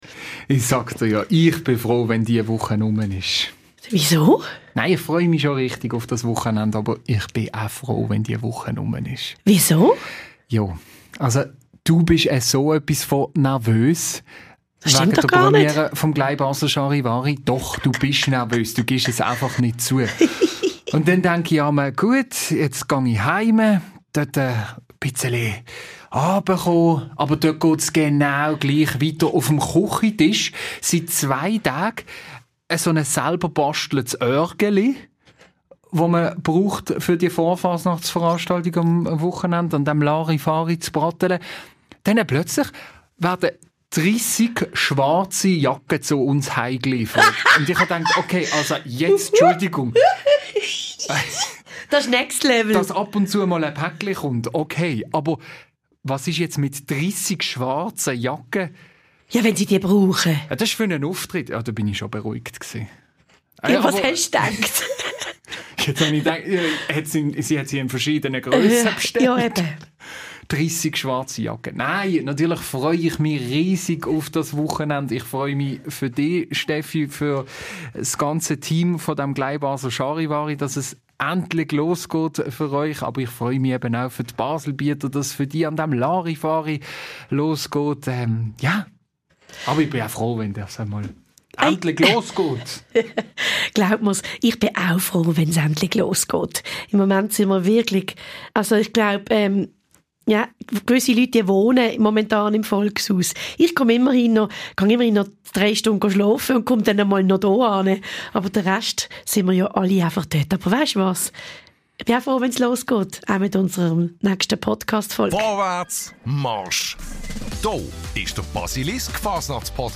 Nachdem wir in der letzten Episode eine Künstlerin gehört haben, meldet sich diesmal eine Verkäuferin der Blaggedden zu Wort und gibt Einblicke in diese ganz besondere Fasnachts-Tradition.